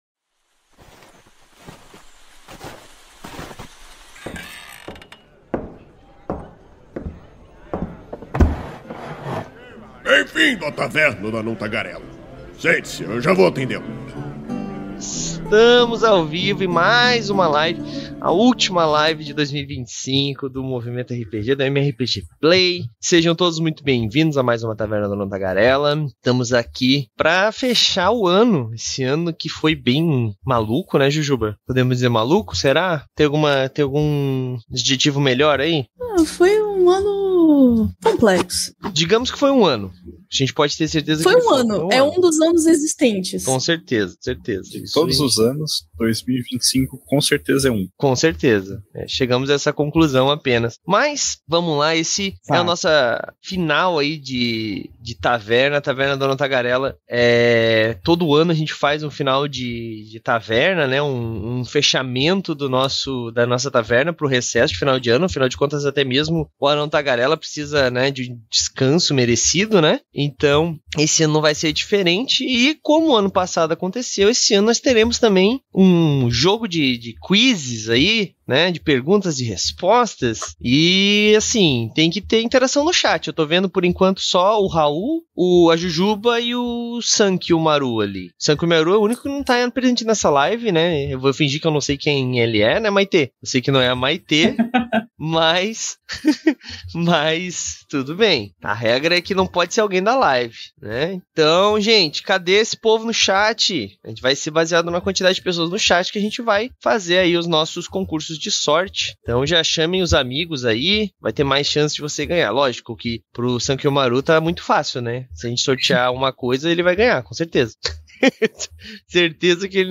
Vanha saber quais foram os conteúdos e rsultados do MRPG em 2025, bem como ter uma prévia do que esperar para 2026 na nossa comunidade. A Taverna do Anão Tagarela é uma iniciativa do site Movimento RPG, que vai ao ar ao vivo na Twitch toda a segunda-feira e posteriormente é convertida em Podcast. Com isso, pedimos que todos, inclusive vocês ouvintes, participem e nos mandem suas sugestões de temas para que por fim levemos ao ar em forma de debate.